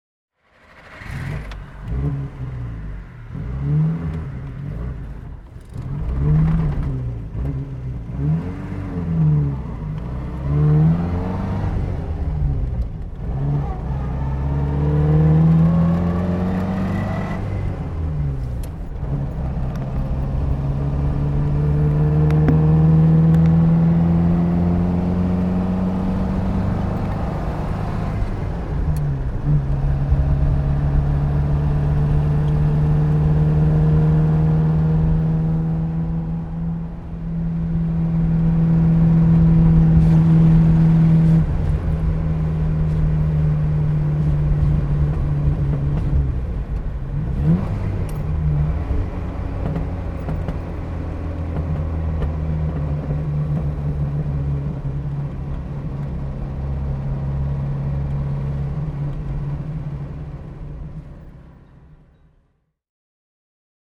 Fiat 1100 B Cabriolet (1949) - Fahrgeräusch (innen)